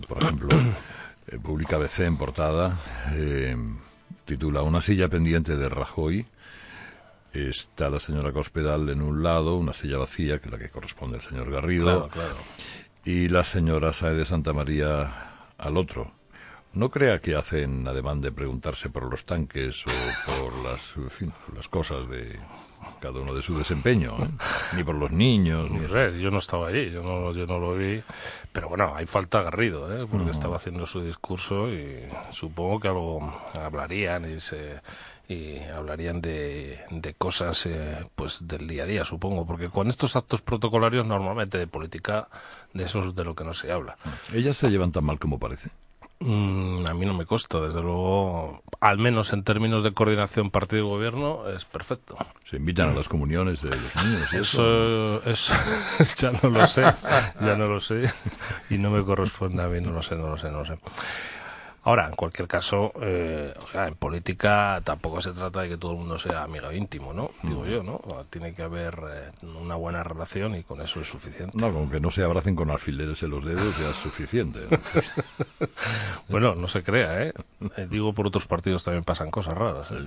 Entrevista con Fernando Mtnez. Maíllo
El coordinador general del PP, Fernando Martínez-Maíllo, se ha pronunciado este jueves en 'Herrera en Cope' sobre la tensa relación entre la ministra de Defensa, María Dolores de Cospedal, y la vicepresidenta del Gobierno, Soraya Sáenz de Santamaría.